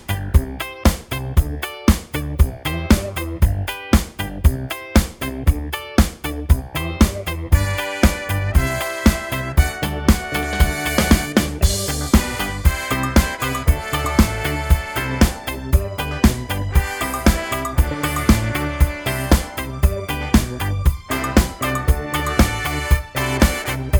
no Backing Vocals Disco 3:09 Buy £1.50